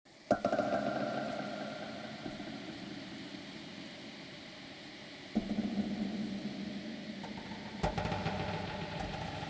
A segment of the "Rice Water" audio file, with an added spatial audio effect, and transposed to a lower frequency. This sound is correlated with the letter "m" on the computer keyboard.